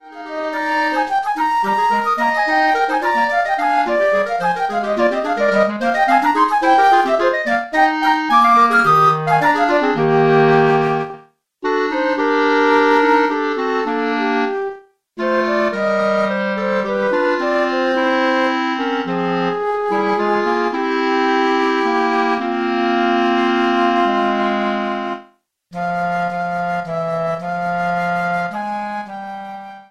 příčnou flétnu
2 Flöten und Klarinette